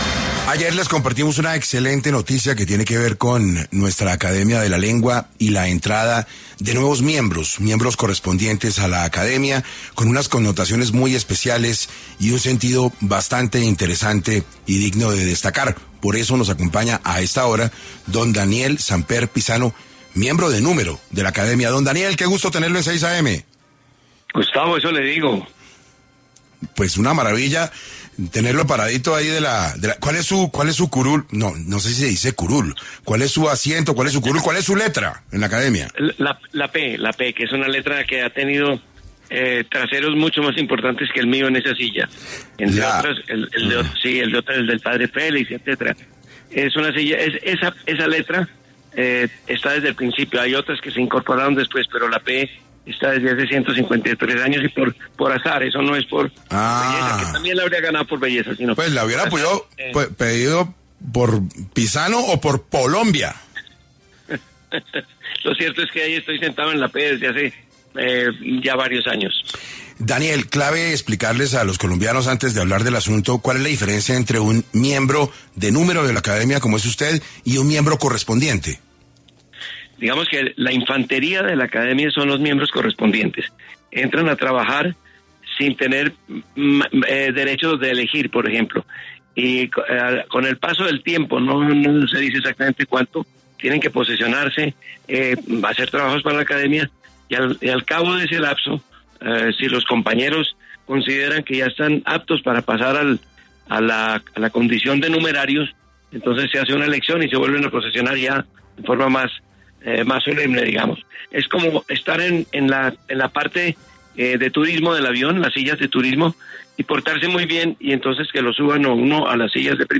En 6AM de Caracol Radio estuvo Daniel Samper Pizano, miembro de número académico de la Academia Colombiana de la Lengua, quien habló sobre la elección de los nuevos miembros y cómo se viene desarrollado el buen uso del castellano en la actualidad